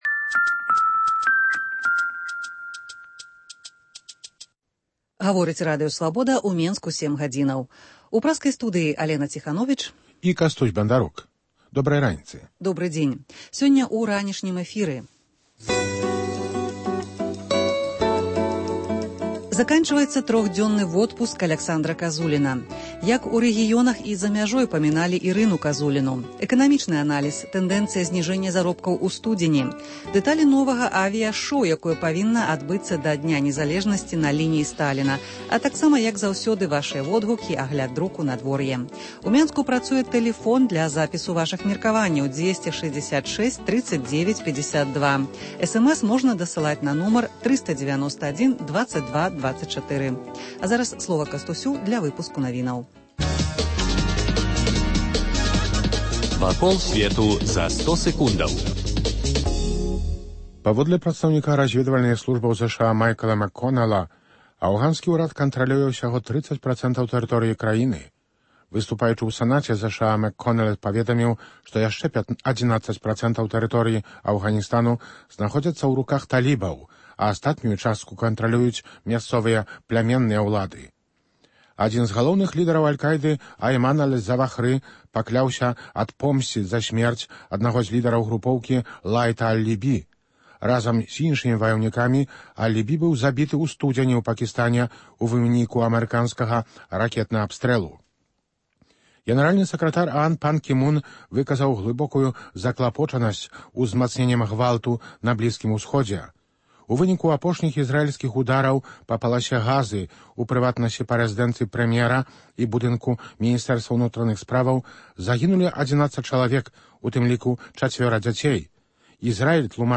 Ранішні жывы эфір
Інтэрвію